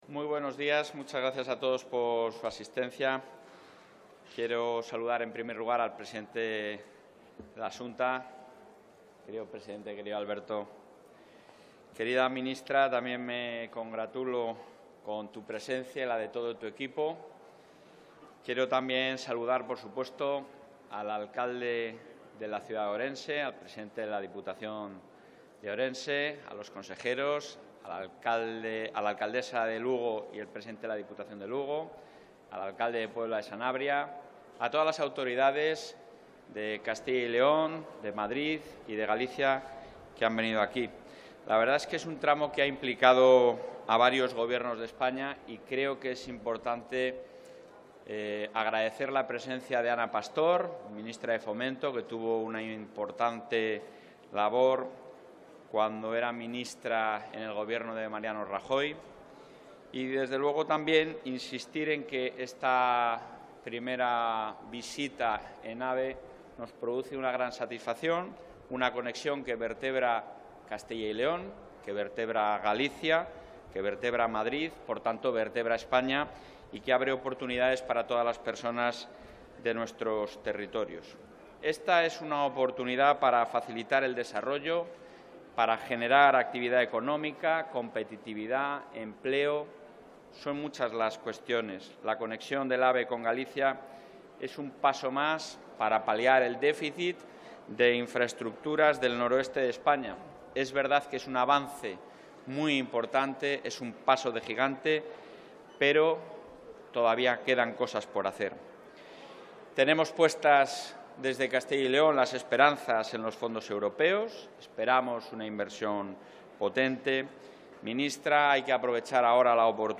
Intervención del presidente de la Junta.
El presidente de la Junta de Castilla y León, Alfonso Fernández Mañueco, ha advertido de que es necesario que el Gobierno central que mire a todo el territorio de la misma manera y lograr así un impulso simétrico. Así lo ha advertido desde Orense después de cubrir en un tren de pruebas el trayecto entre Zamora y la capital gallega, el primer AVE que ha cubierto el trazado entre la estación de la localidad zamorana de Pedralba de la Pradería y Orense.